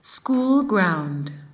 p1_s3_schoolground.mp3